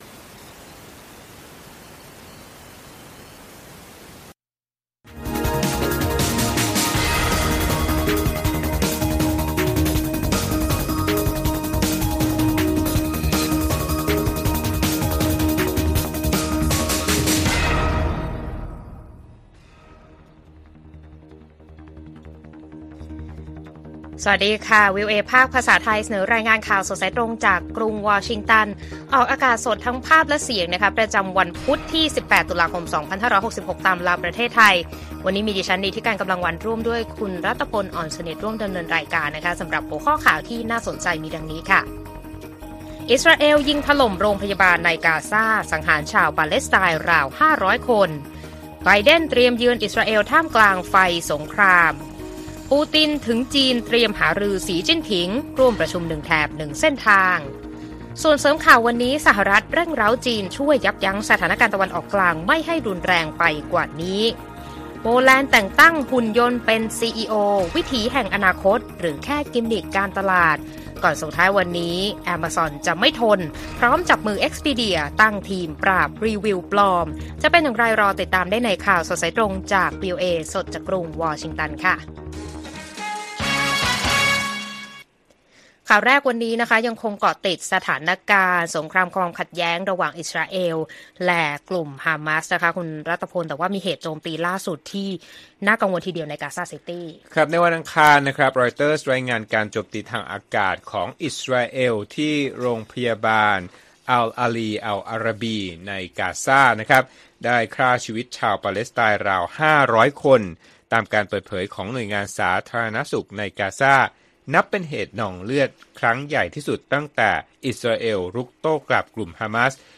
ข่าวสดสายตรงจากวีโอเอ ไทย พุธ 18 ตุลาคม 2566